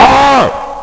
snd_201_BossDeath.mp3